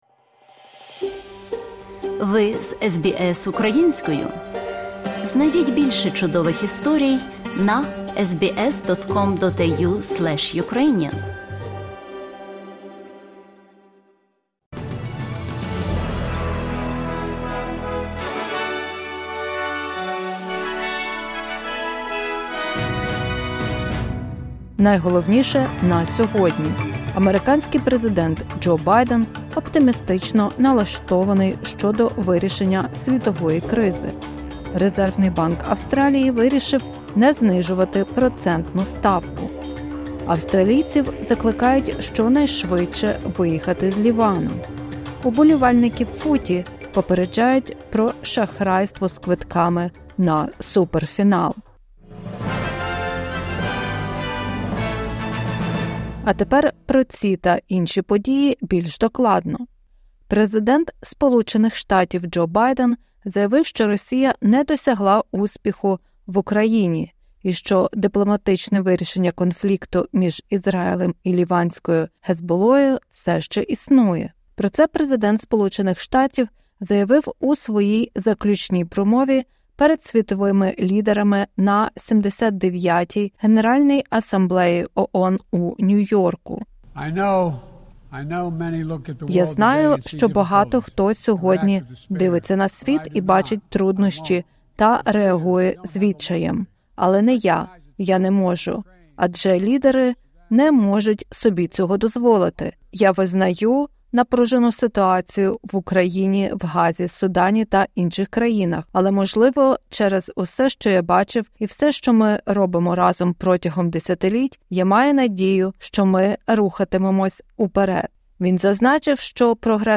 Огляд SBS новин українською мовою.